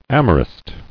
[am·o·rist]